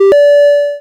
victory chime
coin notification success victory sound effect free sound royalty free Sound Effects